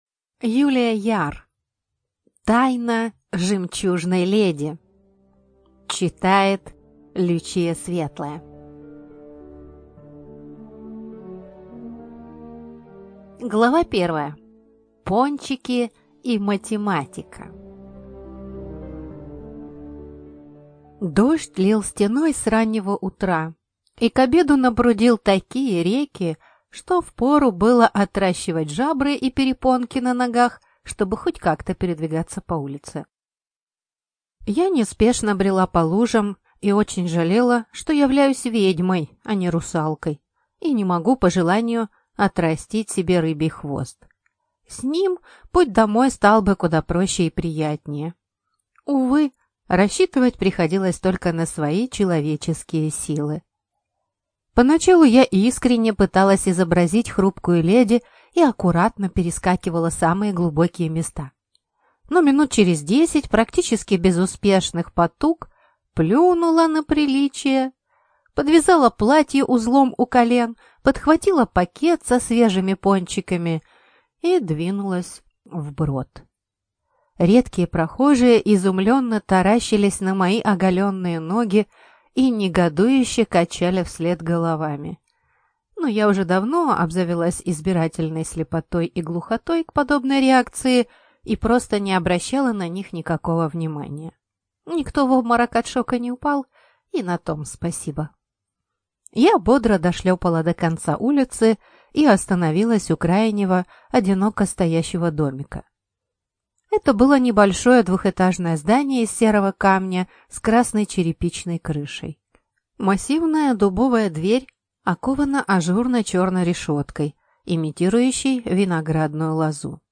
ЖанрЛюбовная проза, Фэнтези